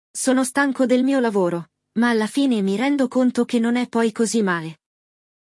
Primeiro, você ouve um diálogo autêntico em italiano. Em seguida, explicamos o que foi dito, destacamos vocabulário útil e, o mais importante, te incentivamos a repetir cada palavra e frase para treinar a pronúncia.